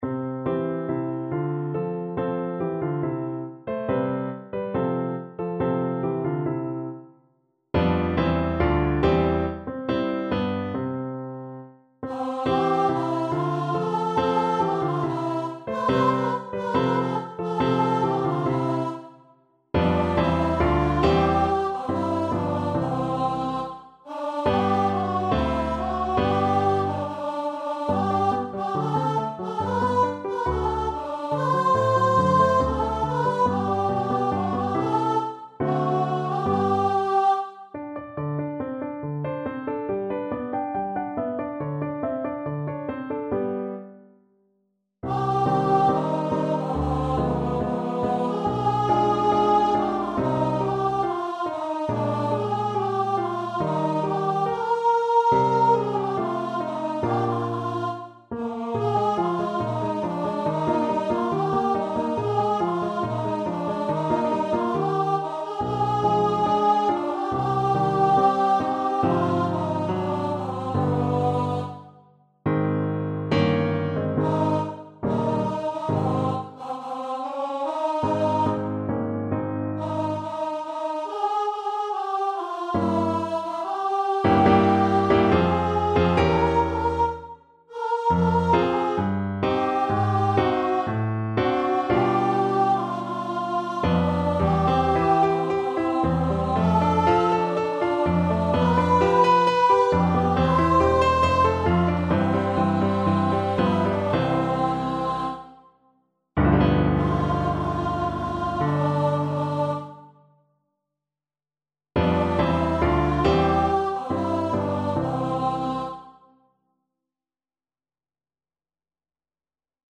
Free Sheet music for Contralto Voice
Contralto Voice
C major (Sounding Pitch) (View more C major Music for Contralto Voice )
= 70 Allegretto